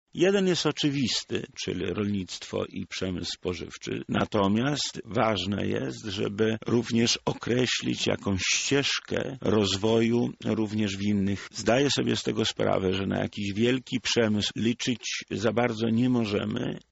Polityk Prawa i Sprawiedliwości był gościem Porannej Rozmowy Radia Centrum.